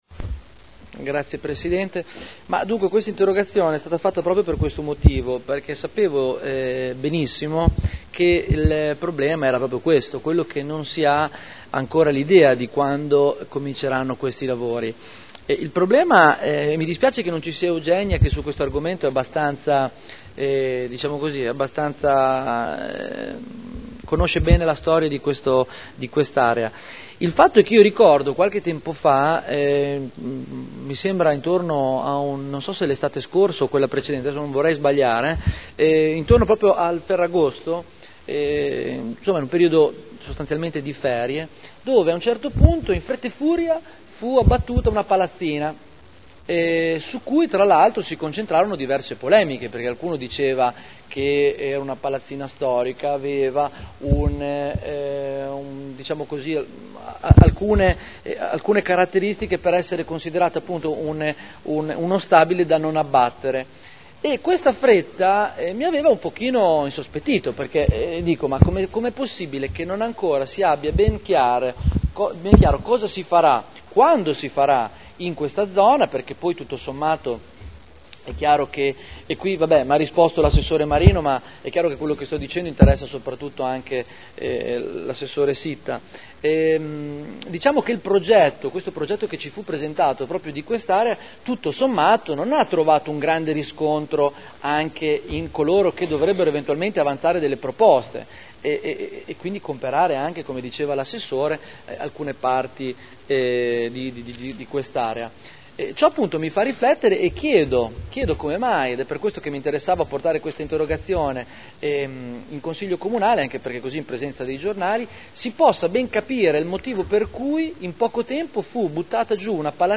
Seduta del 30 gennaio Interrogazione del consigliere Barberini (Lega Nord) avente per oggetto: “Ex AMCM” Replica